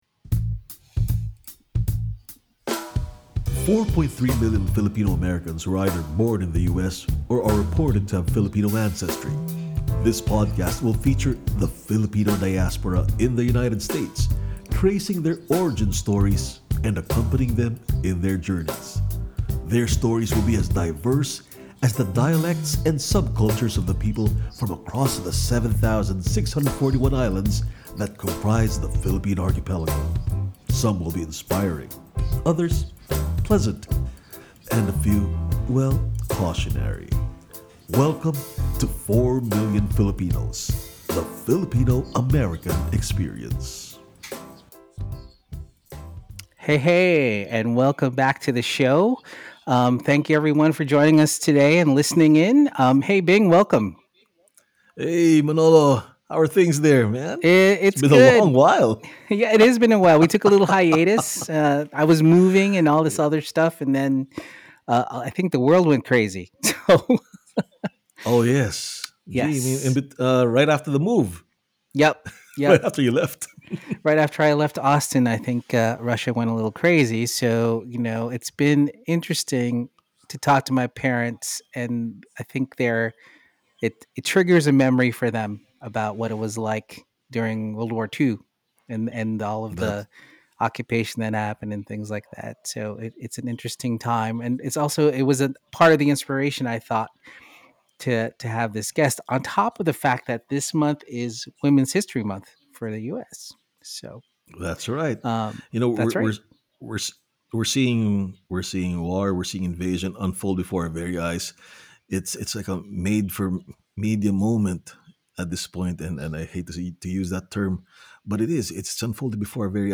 Podcast Interviews – Cyd Publishing